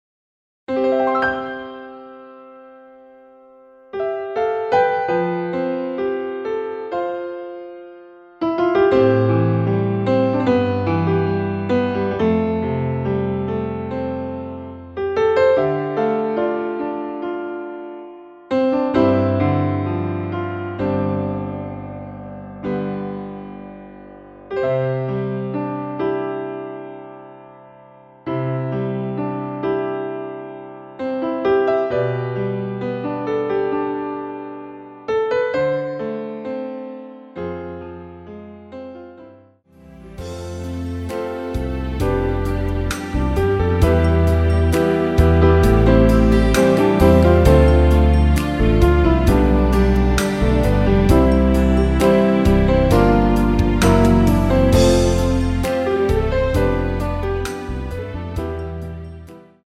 내린 MR
노래방에서 음정올림 내림 누른 숫자와 같습니다.
앞부분30초, 뒷부분30초씩 편집해서 올려 드리고 있습니다.
중간에 음이 끈어지고 다시 나오는 이유는